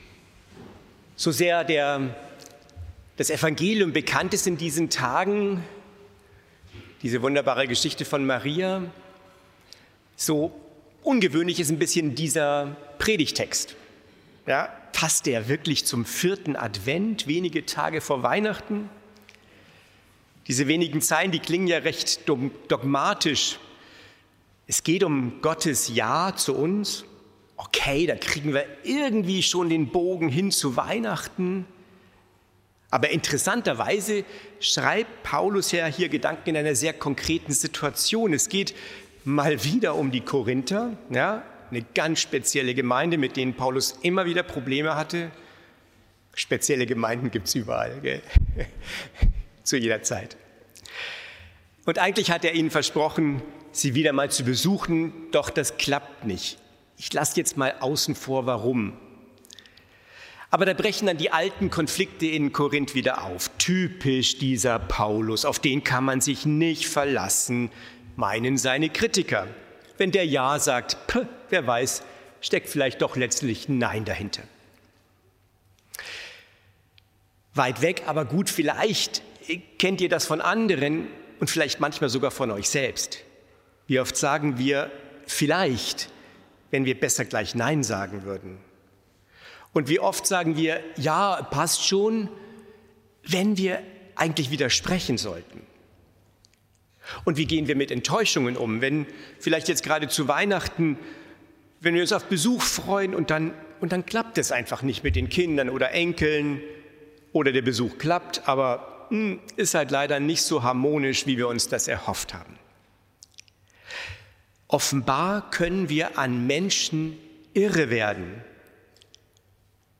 Predigt vom 14.12.2025 Spätgottesdienst